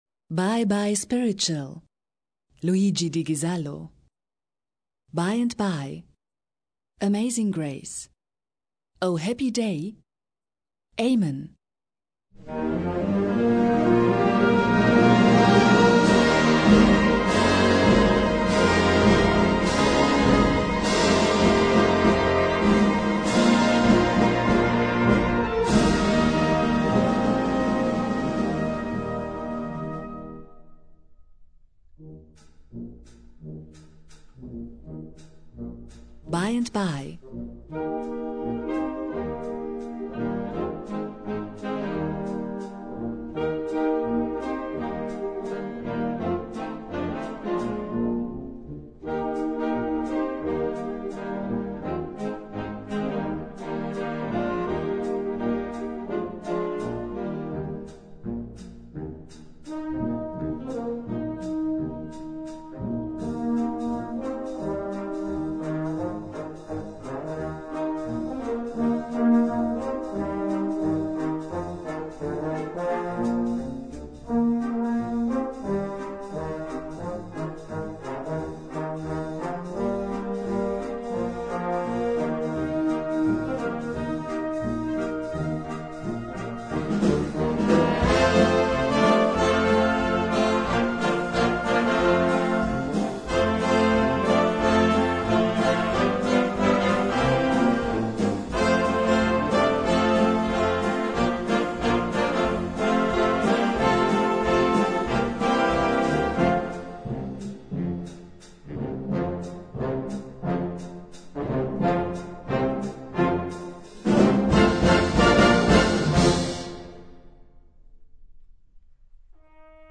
Gattung: Spiritual-Medley
Besetzung: Blasorchester